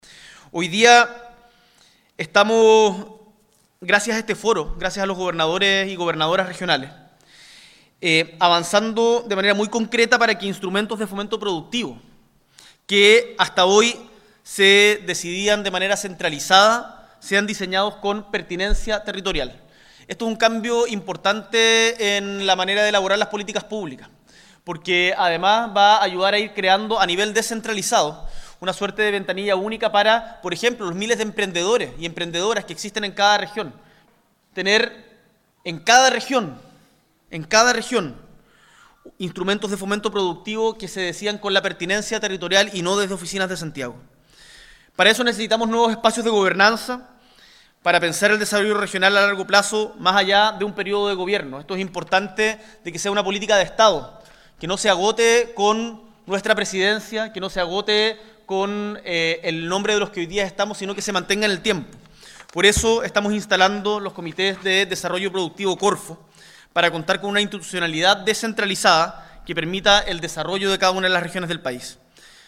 En el Foro por la Descentralización que se realizó este martes en Frutillar, el presidente de la república anunció acciones para avanzar en otorgar más poder a las regiones.